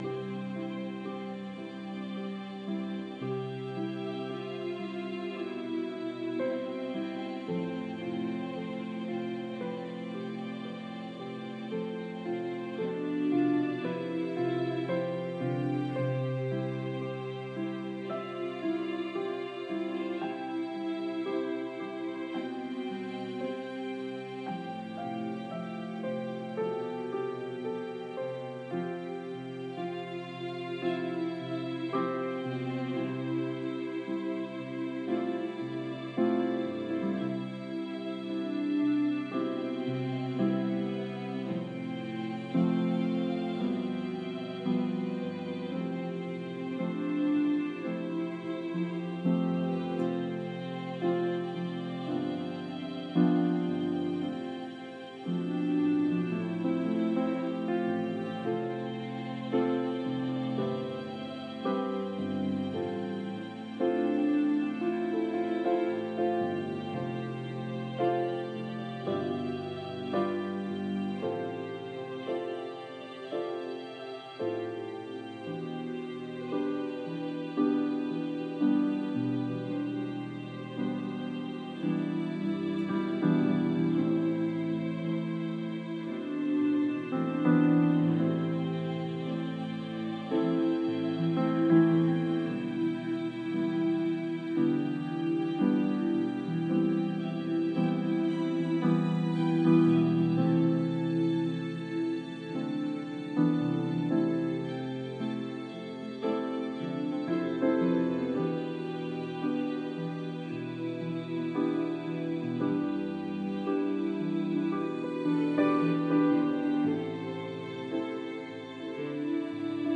Frustration 2 - strings mess